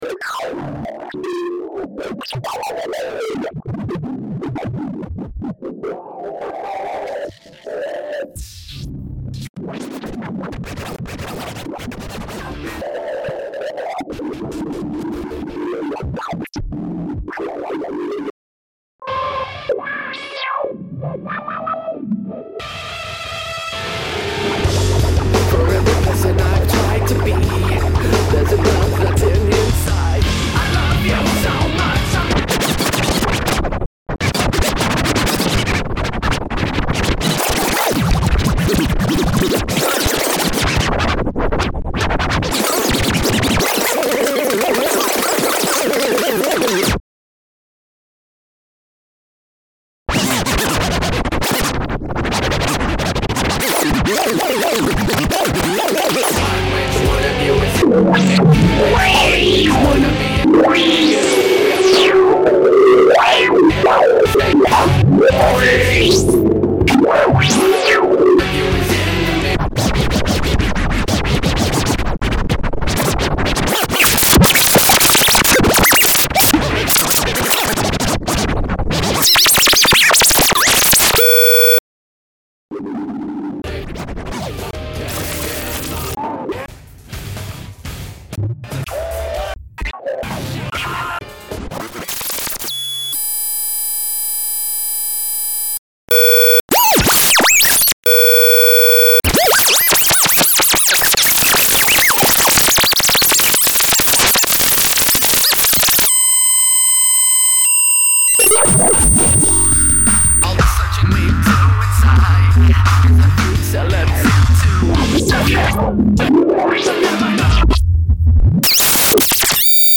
Tags: dj turntable mixes demos dj demos